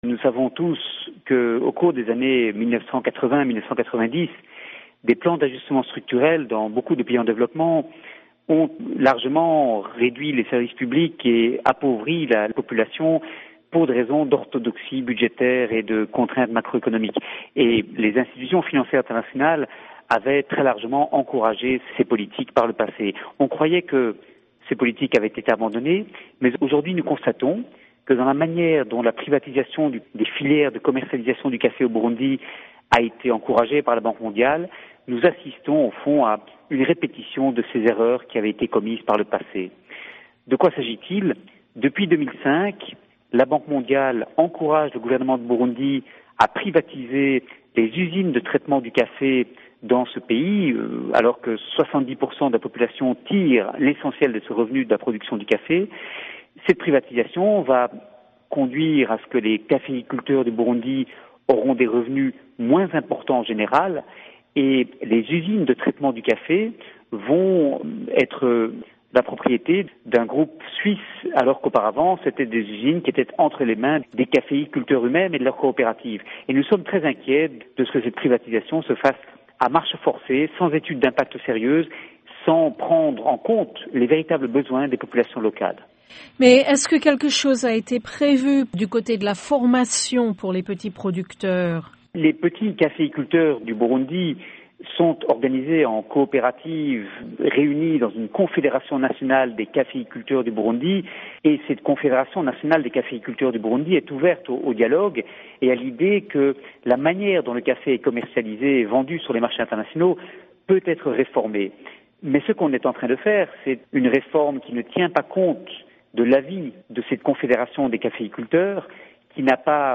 Ecoutez Olivier de Schutter